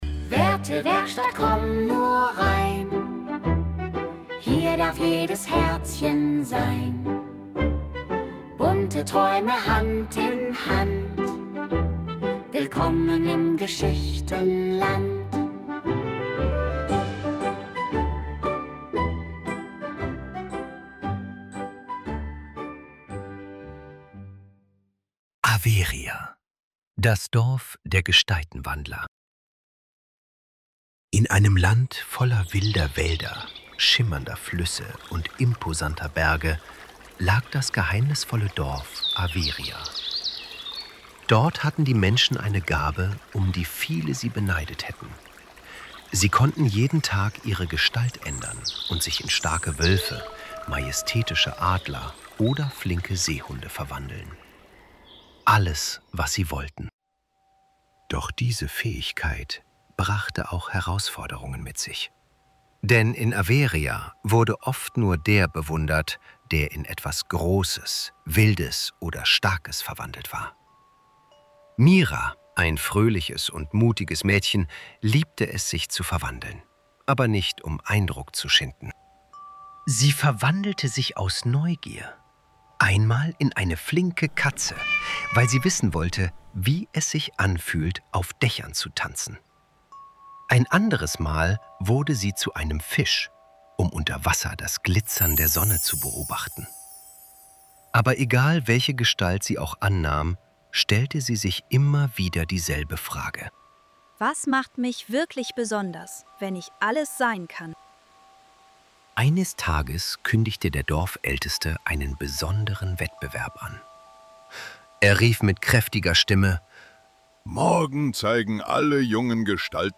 Averia-Hörbuch | Werte-Werkstatt
Kostenloses Hörkapitel - Taucht ein in unsere liebevoll erzählten Geschichten aus Kleine Herzen, Große Werte.